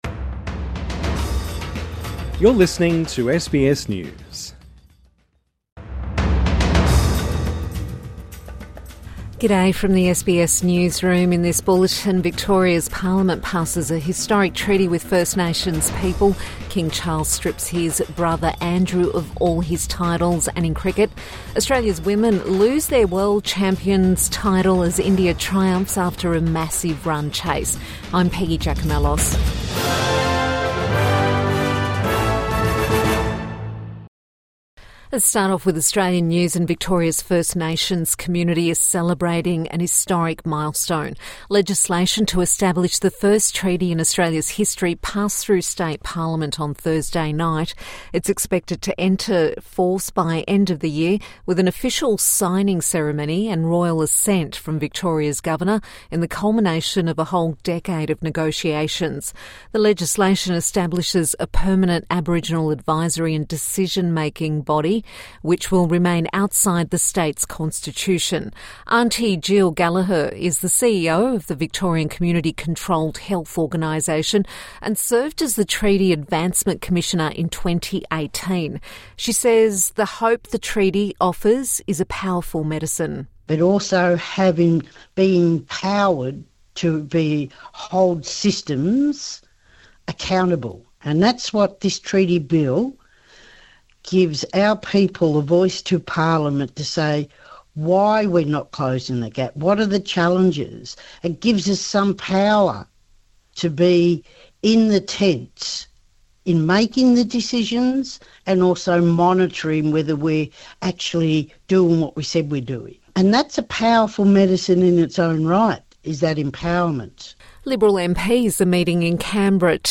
Victoria makes history with Australia's first Indigenous treaty | Midday News Bulletin 31 October 2025